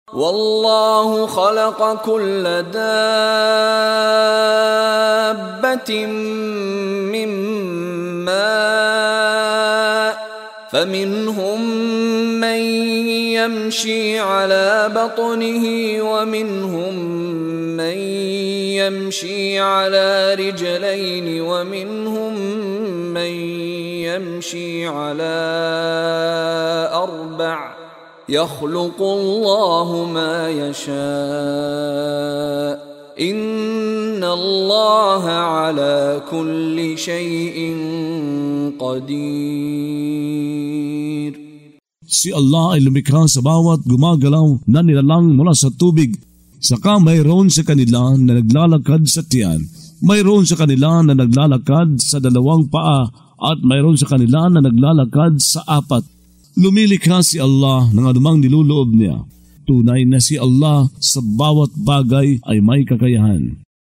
Pagbabasa ng audio sa Filipino (Tagalog) ng mga kahulugan ng Surah An-Nur ( Ang Liwanag ) na hinati sa mga taludtod, na sinasabayan ng pagbigkas ng reciter na si Mishari bin Rashid Al-Afasy. Ang pag-anyaya sa kalinisang puri at ang pangangalaga sa mga dangal.